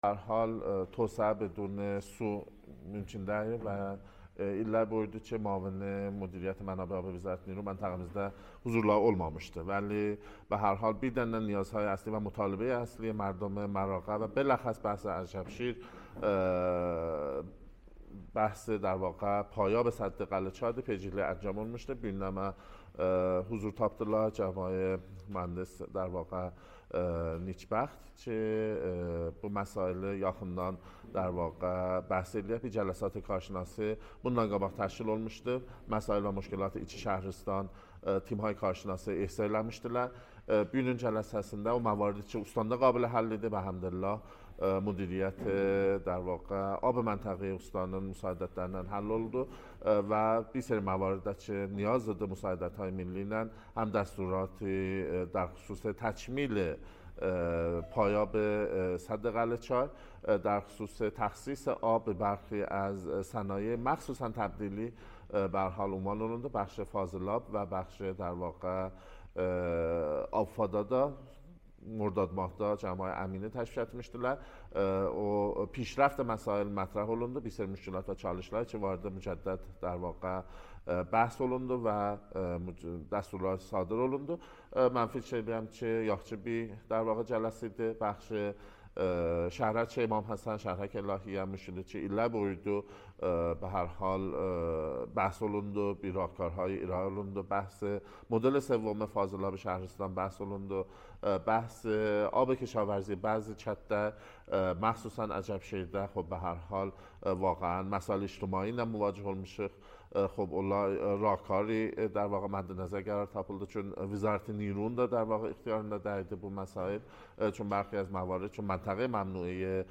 فرماندار عجبشر نیز در گفت‌وگو با خبرنگار ایرنا با بیان اینکه مسایل حوزه آب این شهرستان از جمله تکمیل پایاب سد قلعه‌چای هم در این جلسه و با حضور معاون وزیر نیرو مطرح شد، افزود: تکمیل این طرح با توجه به ظرفیت‌های بخش کشاورزی عجبشیر، از اولویت‌های شهرستان در دولت چهاردهم است.